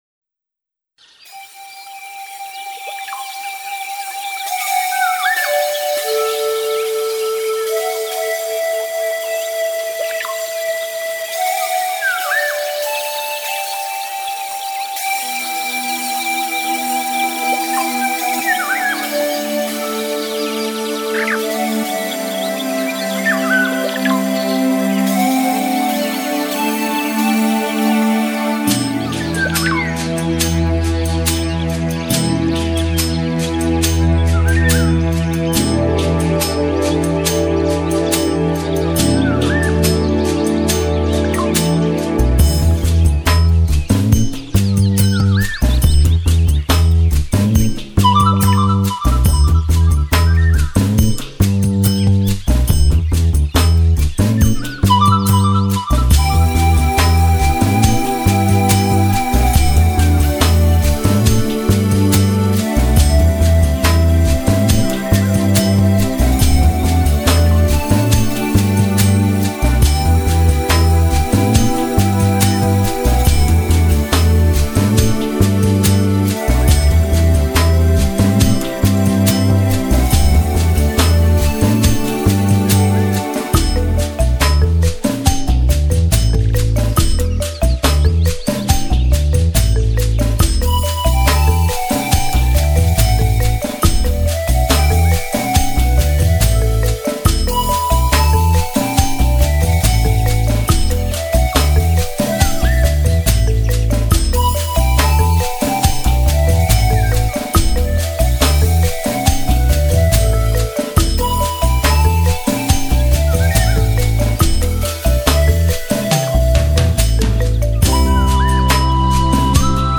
把握住你的方向盘 本辑发烧录音，专供汽车音响使用，相信将使您的爱 车成为可以移动的音乐厅。
让这首曲子更有人文色彩， 开在传神的音场中添加静谧舒缓的本质！